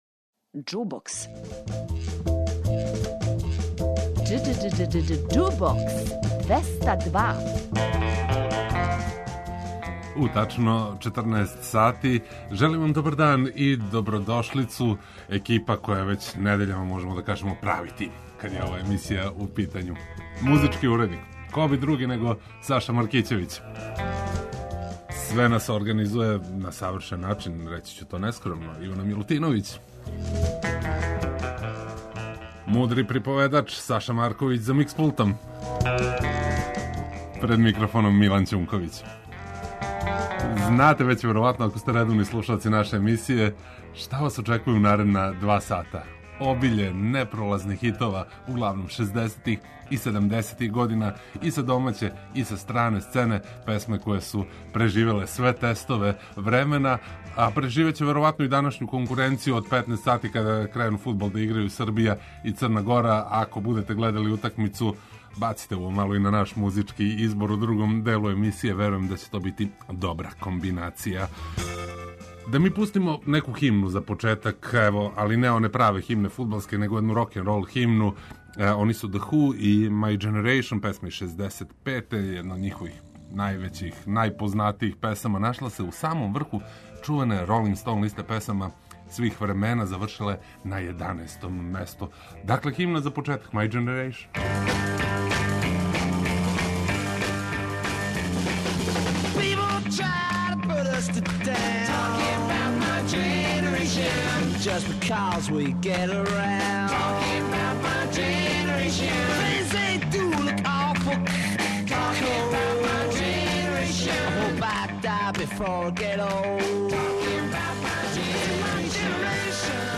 преузми : 54.57 MB Џубокс 202 Autor: Београд 202 Уживајте у пажљиво одабраној старој, страној и домаћој музици.